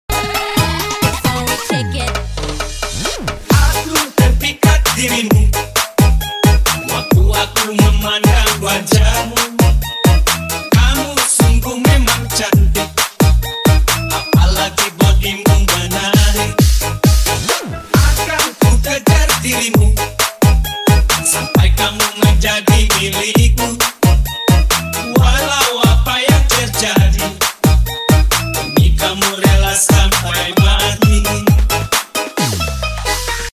ASMR CHITATO LITE SALMON TERIYAKI sound effects free download